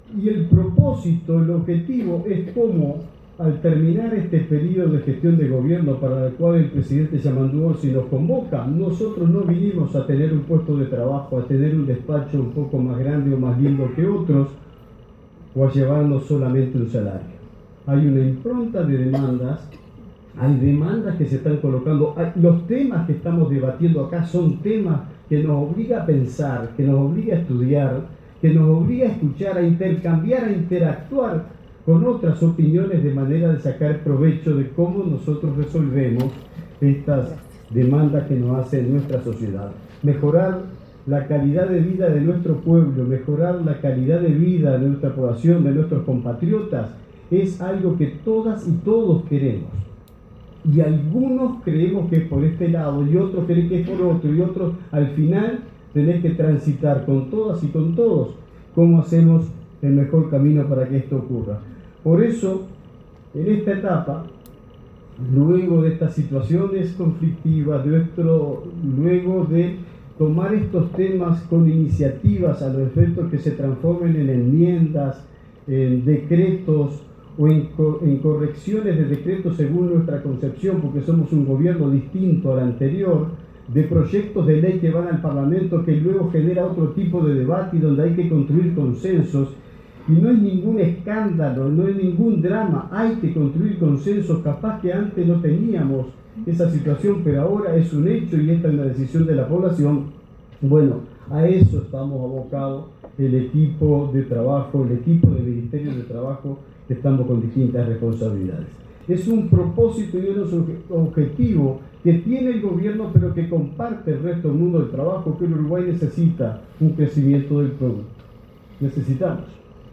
Palabras del ministro de Trabajo y Seguridad Social, Juan Castillo
El ministro de Trabajo y Seguridad Social, Juan Castillo, disertó sobre empleo y relaciones laborales, durante un seminario organizado por la